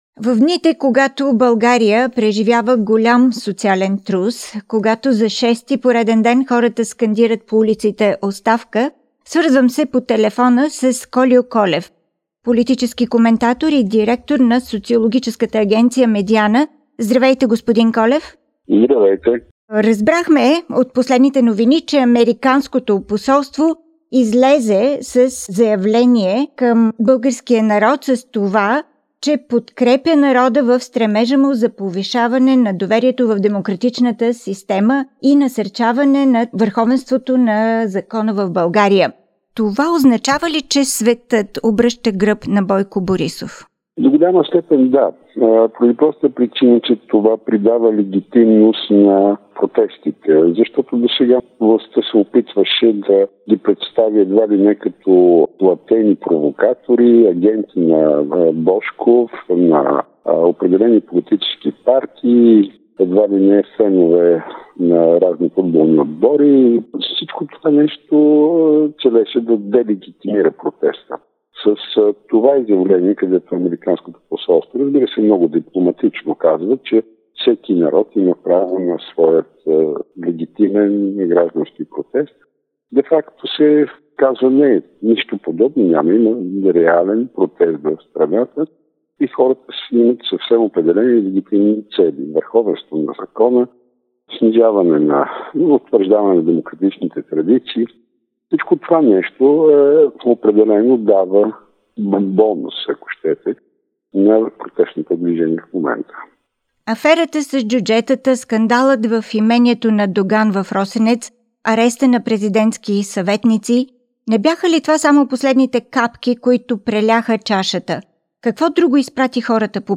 Exclusive interview for Bulgarians in Australia